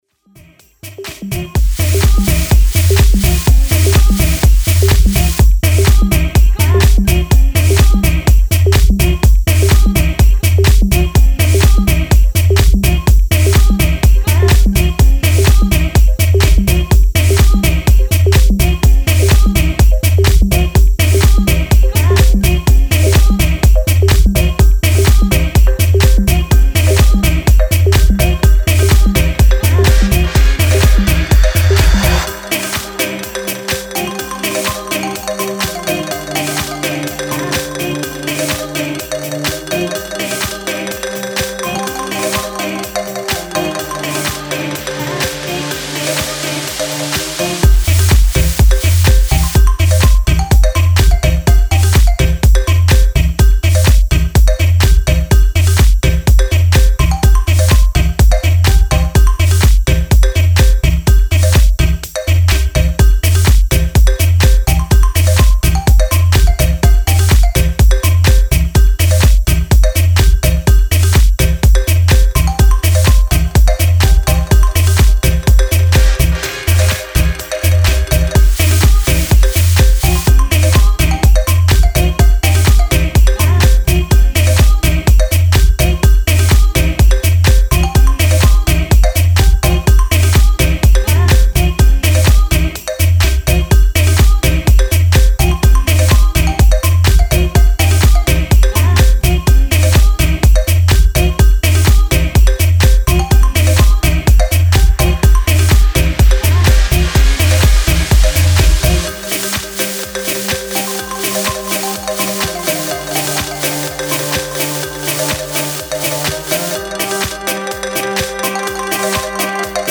Style: Tech House / Minimal